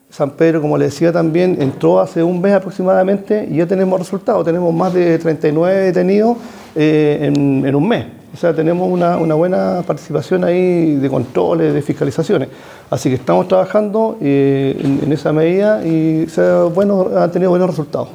El general de zona de Carabineros, Renzo Miccono, aseguró que se encuentran trabajando en la zona, más aún luego que se conociera que un sector de la San Pedro de la Paz se encuentra bajo el plan Calles Sin Violencia.
cuna-renzo-miccono.mp3